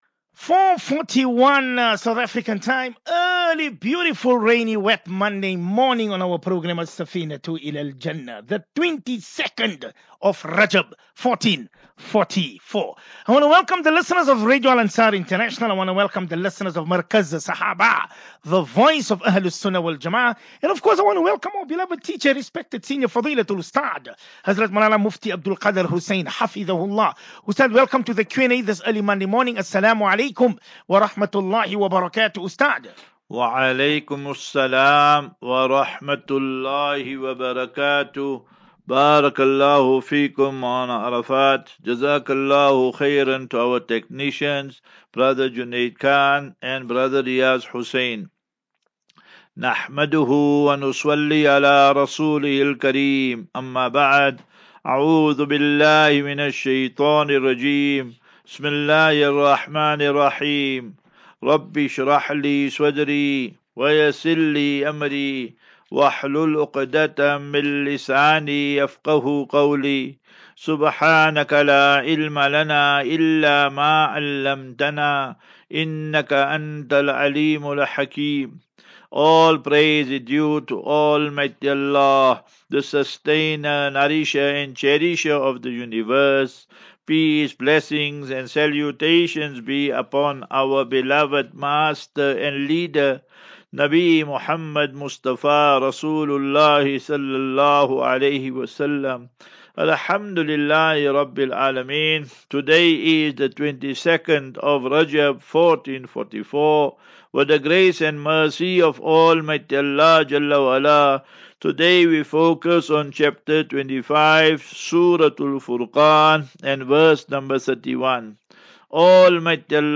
View Promo Continue Install As Safinatu Ilal Jannah Naseeha and Q and A 13 Feb 13 Feb 23- Assafinatu-Illal Jannah 37 MIN Download